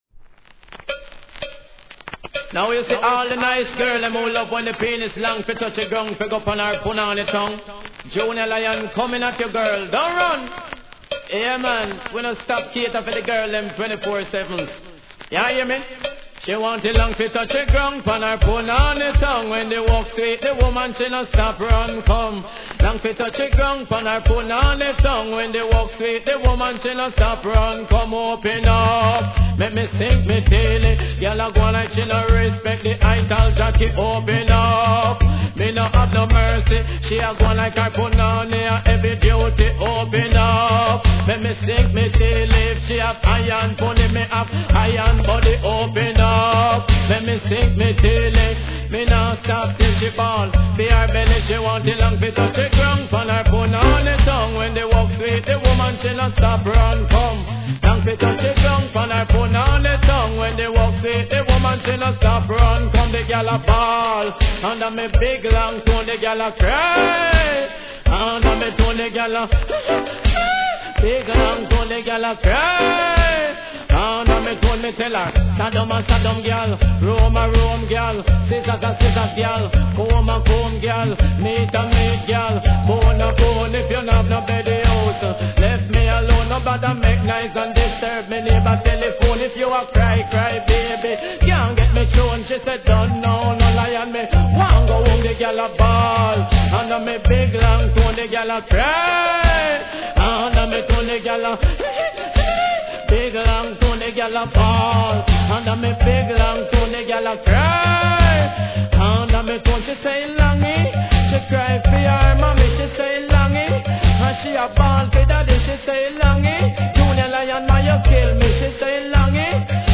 REGGAE
イントロから下炸裂の危険な逸品!! 曲の展開も素晴らしすぎる、変体コレクター要クリックアイテム!!